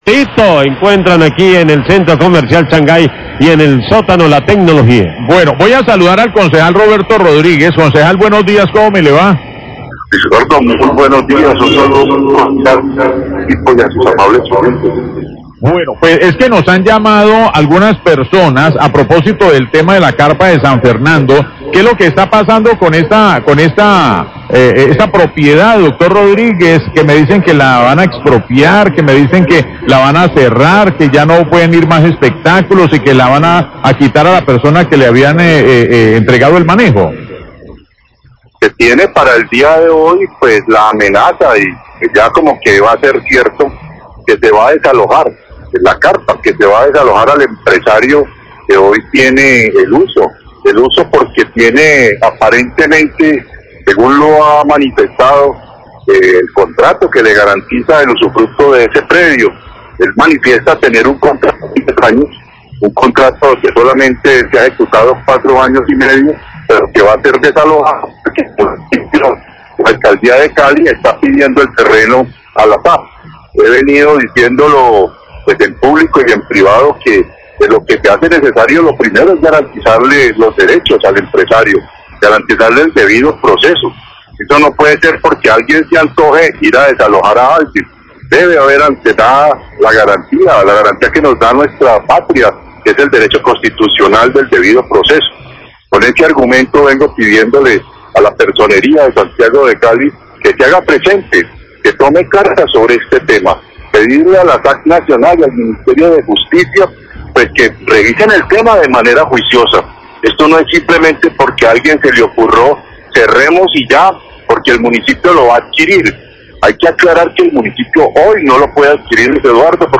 NOTICIAS DE CALIDAD
Concejal Roberto Rodríguez, habla sobre el desalojo de la carpa del Club San Fernando que se realizaría hoy, dice que le ha pedido a la Alcaldía y la personería que se revise el tema y se respete el debido proceso.